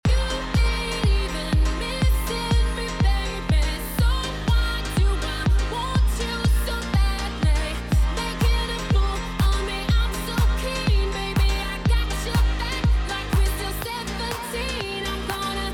17-korg-m1-example.mp3